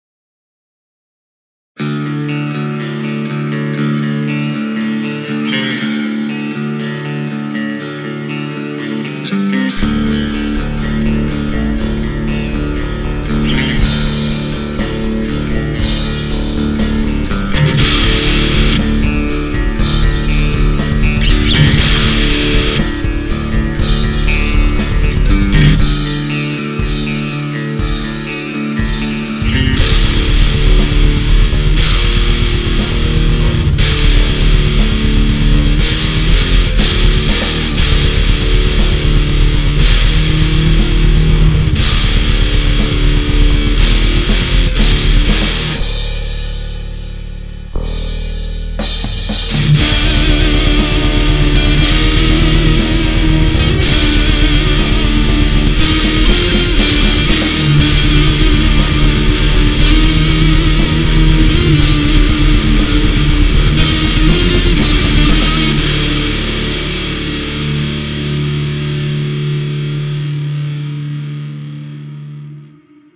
В итоге закончились мои выборы, основанные на Ваших советах покупкой усилителя Orange Dark Terror и кабинетом Marshall 80 ватт 16 Ом 1"12.